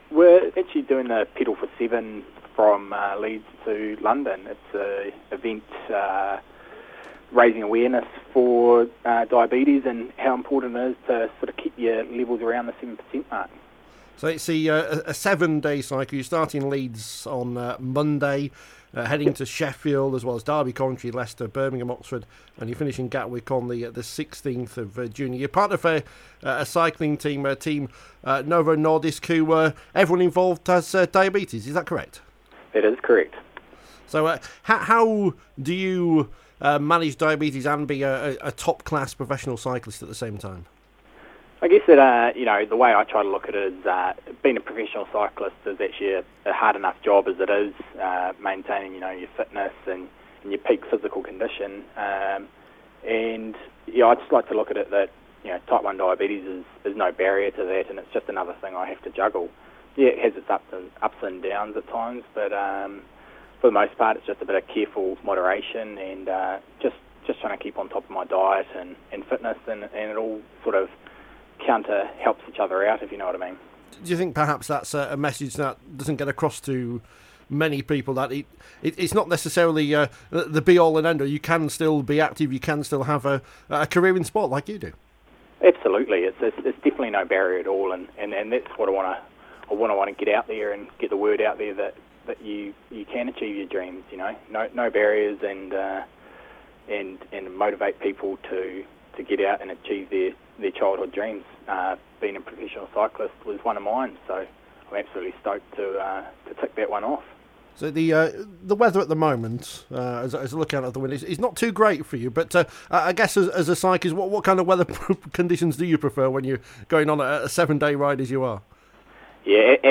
spoke to Radio Yorkshire ahead of the ride